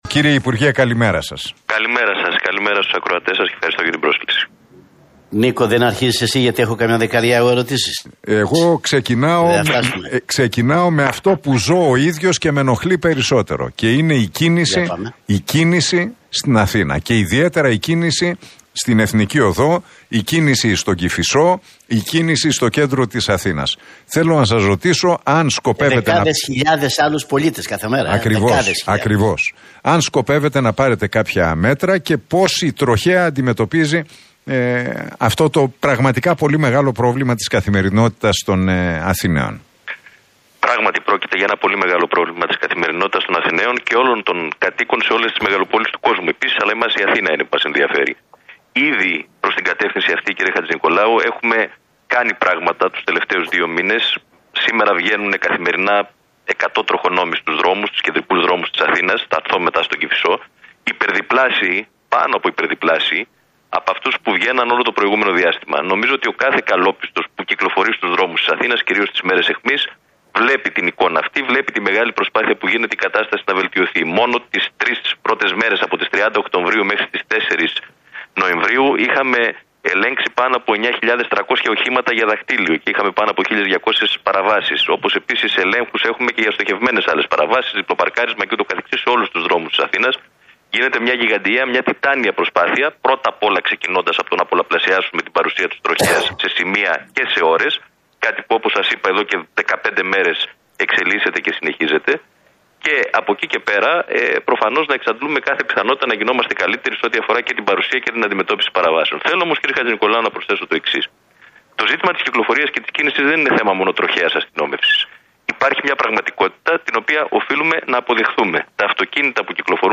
Οικονόμου στον Realfm 97,8: Χρειάζονται έργα για το κυκλοφοριακό, δεν είναι μόνο θέμα Τροχαίας